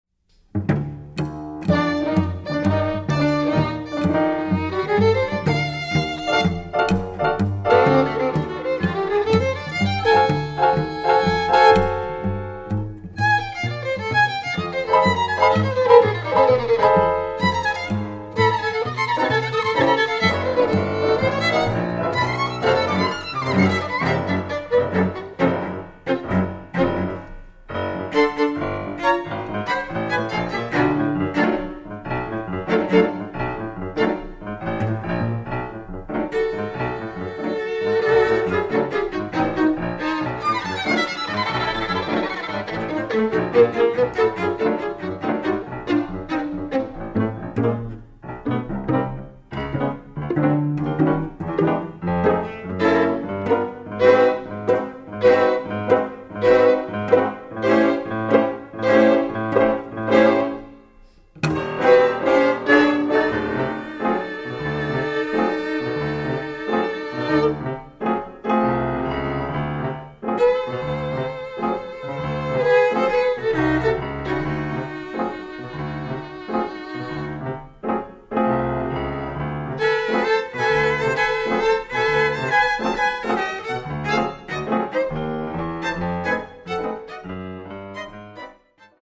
violin, violoncello, piano